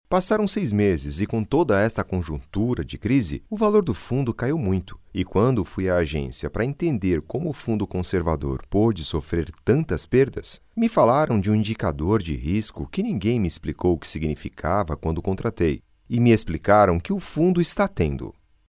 locutor brasil, brazilian voice over